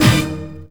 SWINGSTAB 7.wav